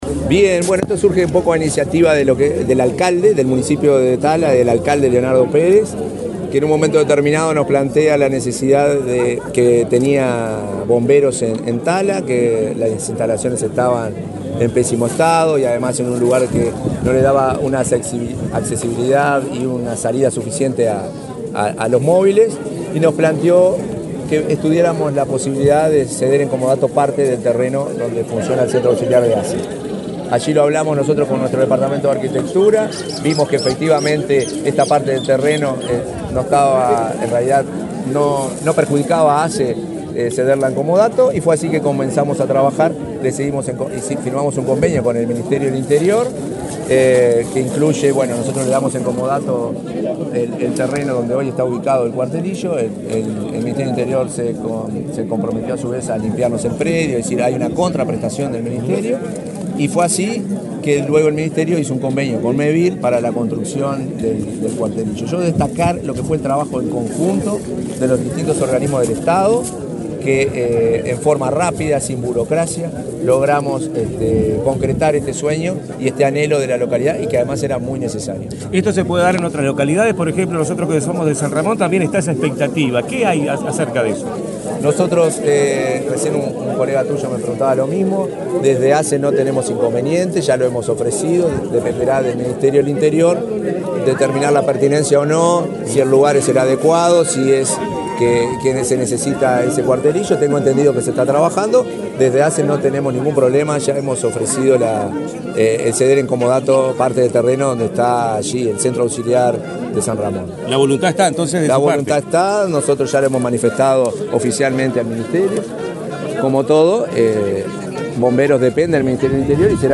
Declaraciones del presidente interino de ASSE, Marcelo Sosa
Declaraciones del presidente interino de ASSE, Marcelo Sosa 18/04/2024 Compartir Facebook X Copiar enlace WhatsApp LinkedIn El presidente interino de ASSE, Marcelo Sosa, dialogó con la prensa, luego de participar de la inauguración del cuartelillo de Bomberos en la localidad de Tala, Canelones.